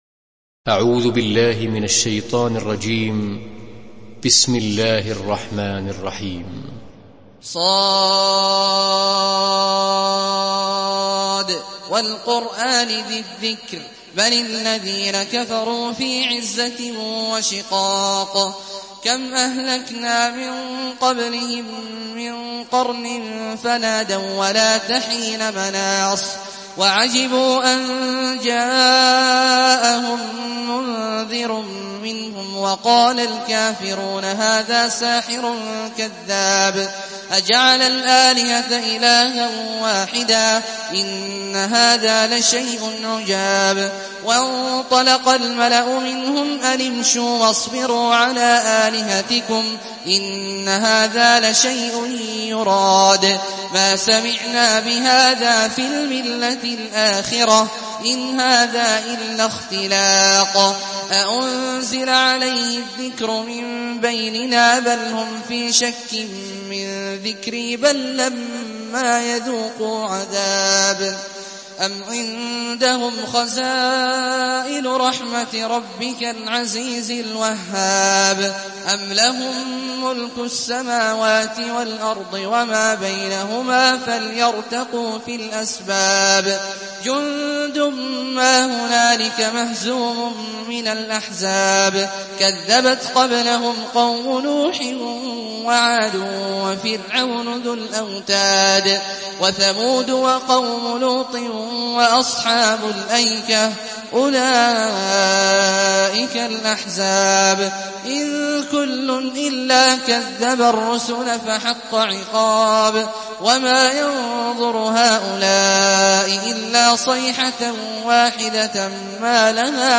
Surah Sad MP3 by Abdullah Al-Juhani in Hafs An Asim narration.
Murattal Hafs An Asim